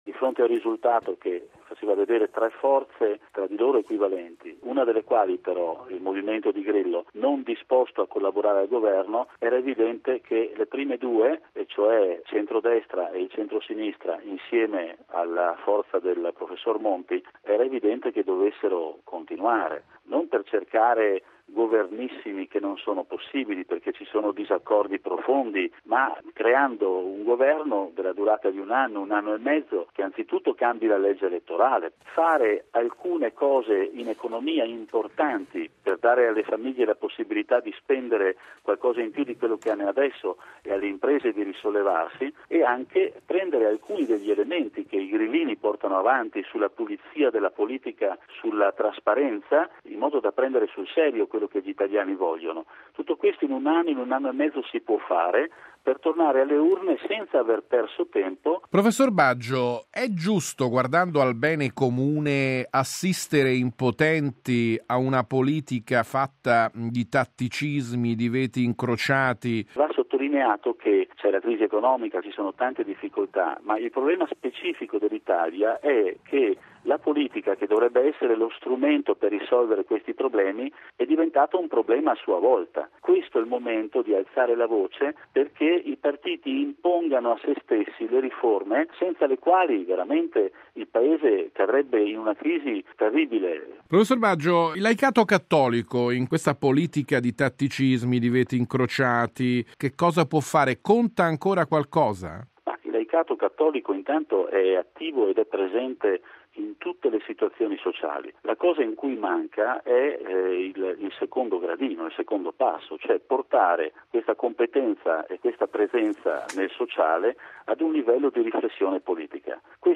Radiogiornale del 10/04/2013 - Radio Vaticana